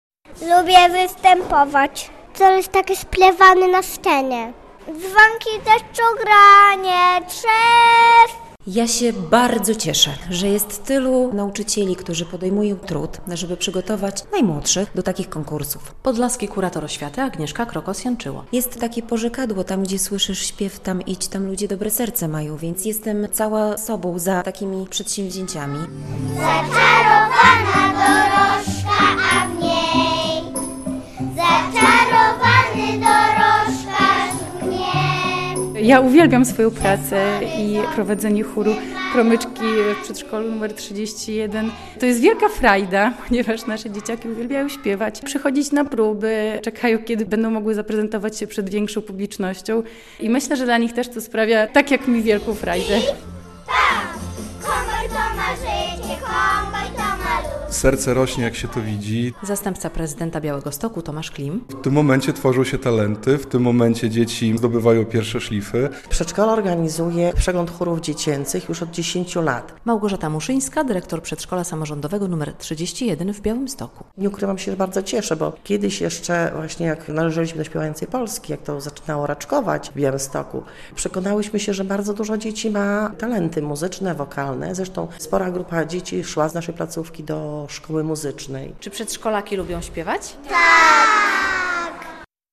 W przyszłości mogą być z nich wielcy artyści. 160. uczniów szkół i przedszkoli z regionu wystąpiło w jubileuszowej X edycji Przeglądu Chórów Dziecięcych „ROZŚPIEWANY BIAŁYSTOK”.
Miejscem prezentacji była sala widowiskowa VII LO w Białymstoku.